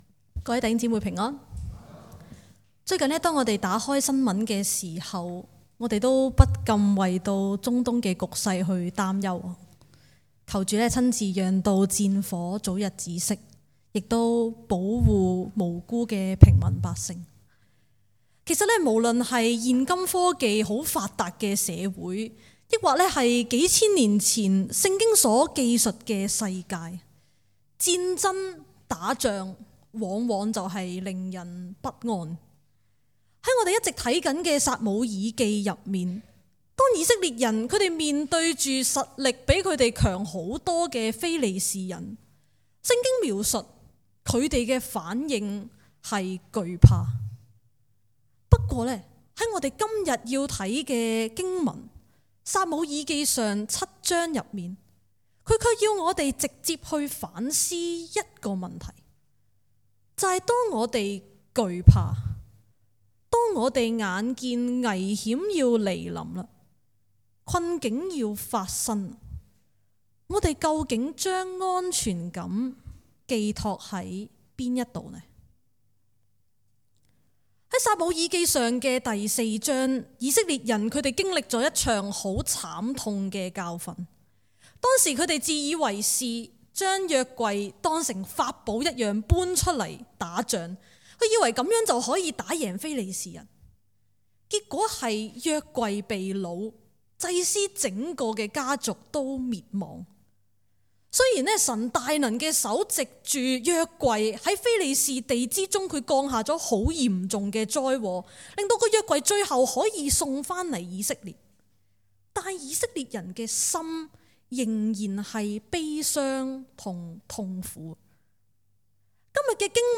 講道重溫
講道類別 : 主日崇拜 經文章節 : 撒母耳記上 7 : 2 - 17